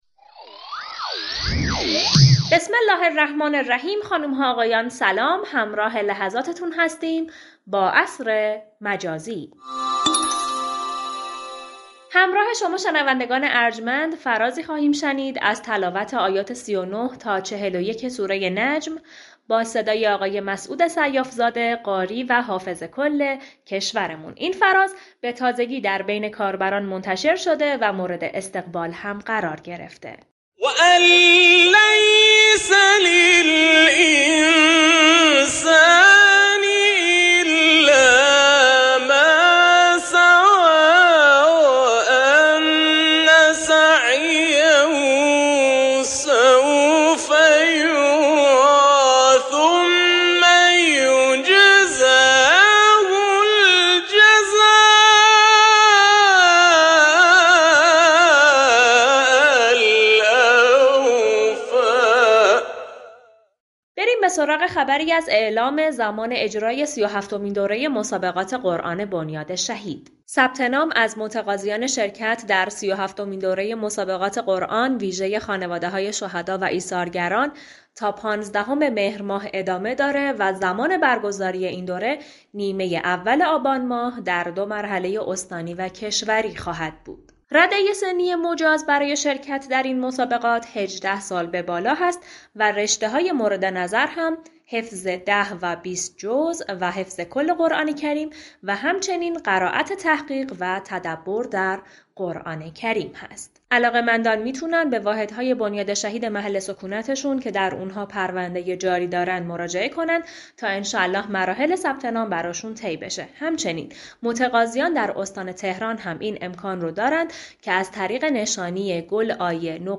شنوندگان ابتدا فرازی از تلاوت آیات 39 تا 41 سوره نجم